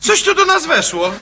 Worms speechbanks
takecover.wav